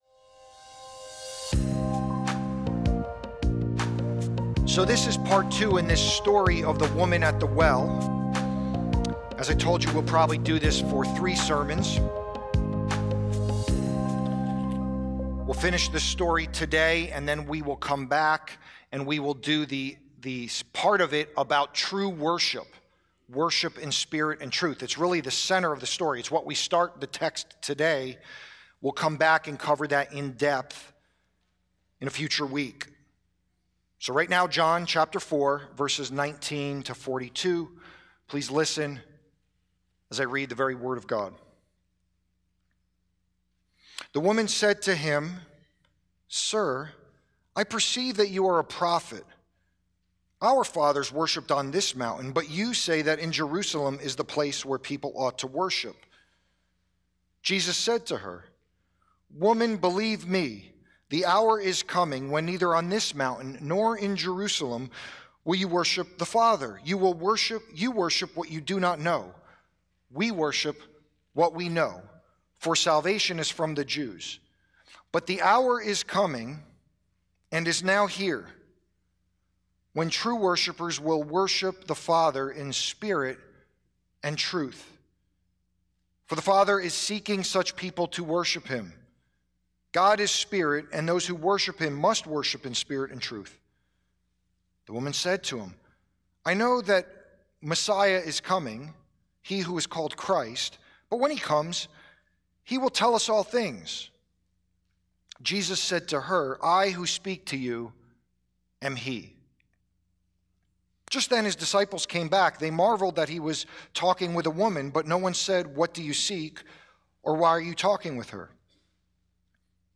Sermon - Why are You Afraid to Share Your Faith?
sermon-sharing-faith.mp3